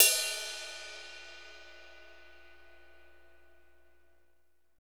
Index of /90_sSampleCDs/Northstar - Drumscapes Roland/CYM_Cymbals 2/CYM_R&B Cymbalsx
CYM R B RD05.wav